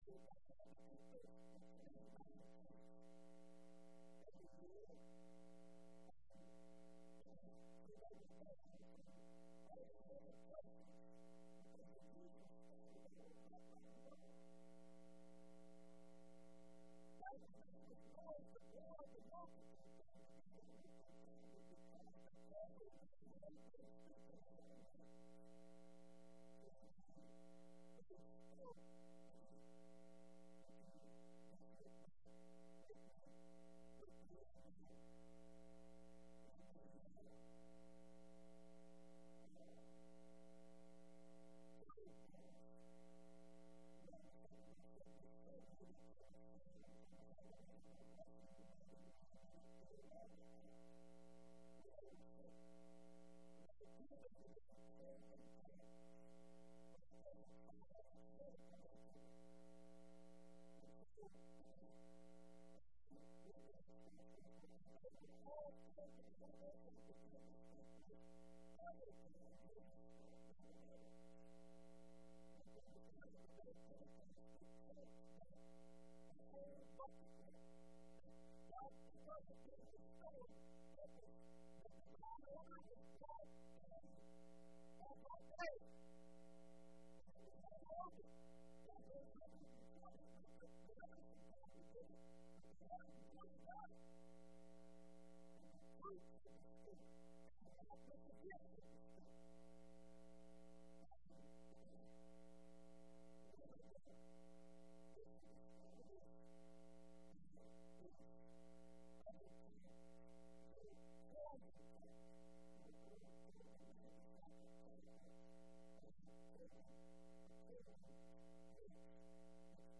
05/02/12 Wednesday Evening Service